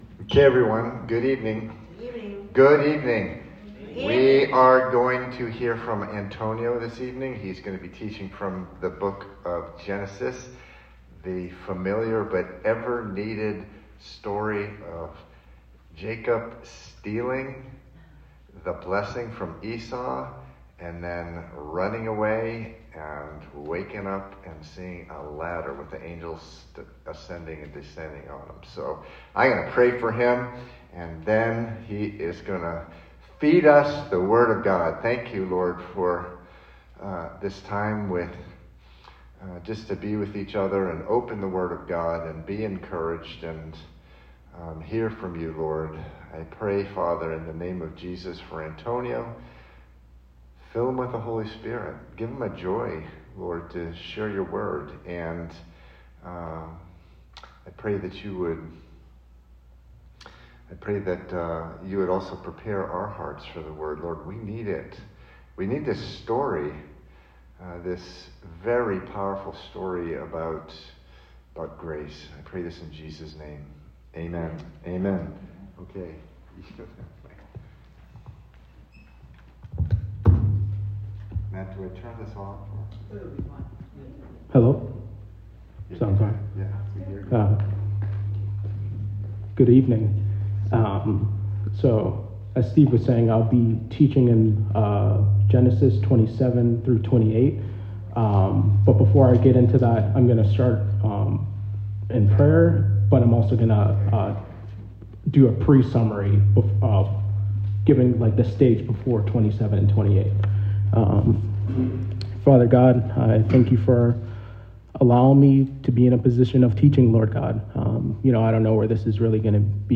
Play Rate Listened List Bookmark Get this podcast via API From The Podcast Listen to in depth teachings through the bible from Calvary Chapel In The City, located in Boston, MA.